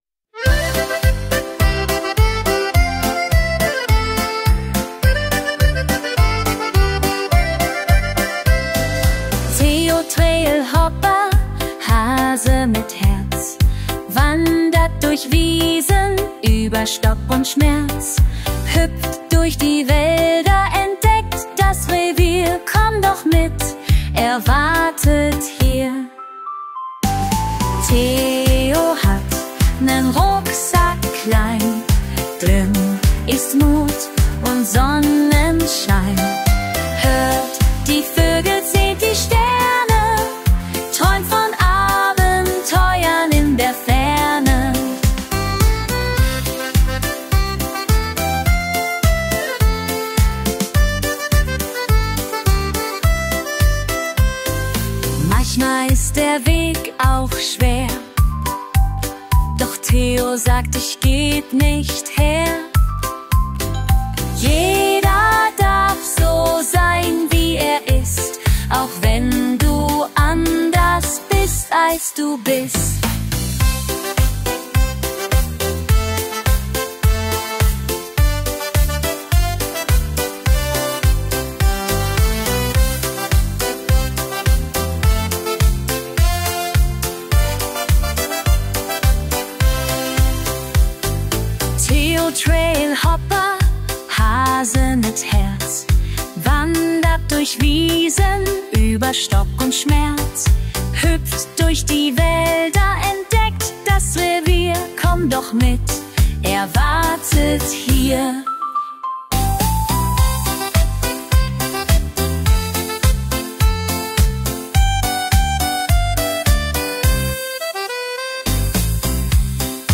Habt ihr schon den offiziellen Theo Trailhopper Song gehört Fröhlich, herzlich und perfekt zum Mitsingen!